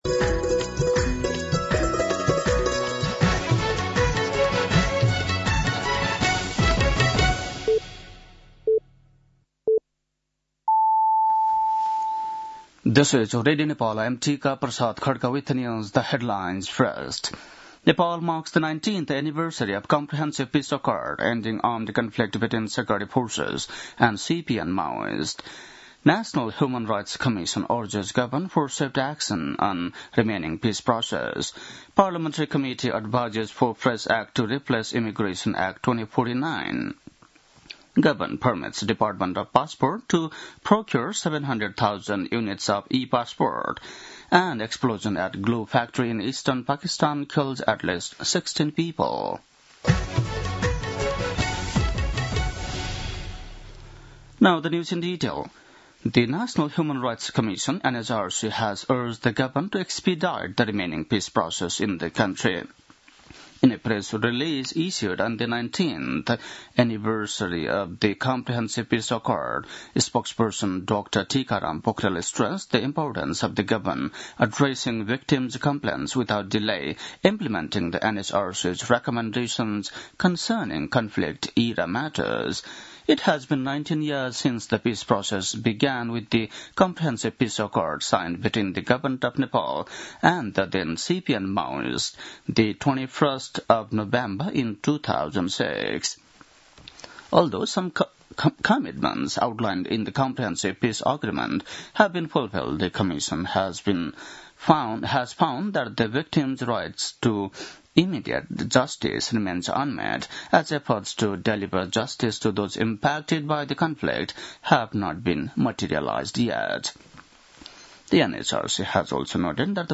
बेलुकी ८ बजेको अङ्ग्रेजी समाचार : ५ मंसिर , २०८२